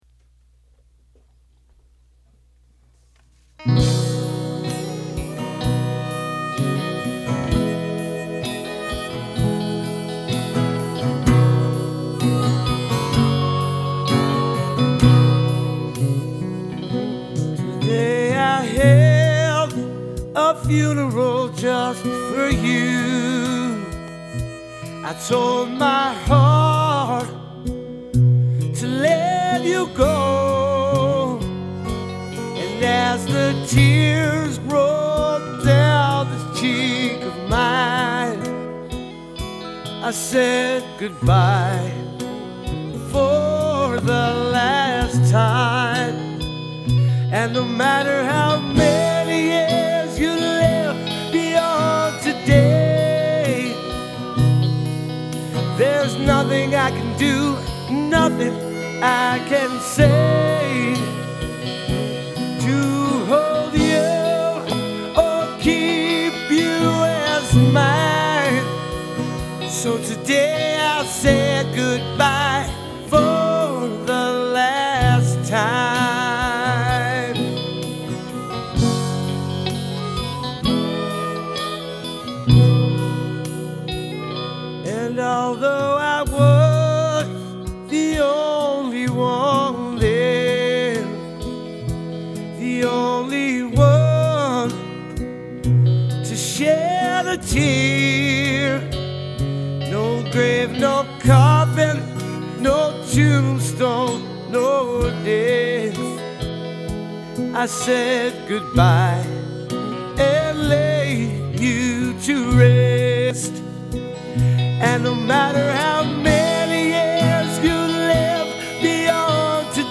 Your Funeral Song Only: Male Vocals of letting go of a relationship